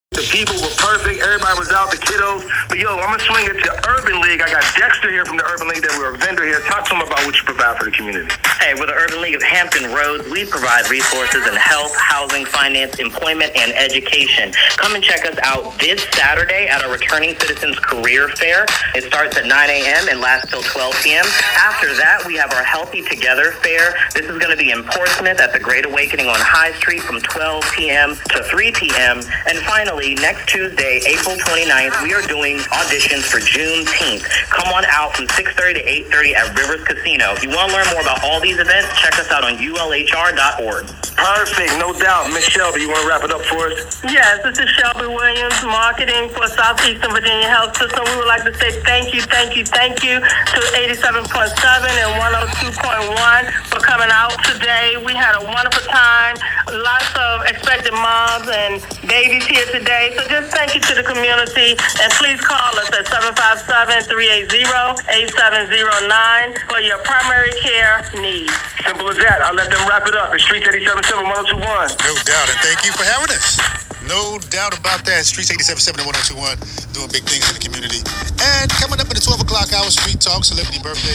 The day also gave us a chance to amplify our work more broadly through a special feature on Streetz Radio 87.7 and 102.1. Talking about our health initiatives on air allowed us to reach even more listeners across Hampton Roads and share how we’re working to close gaps in care and access.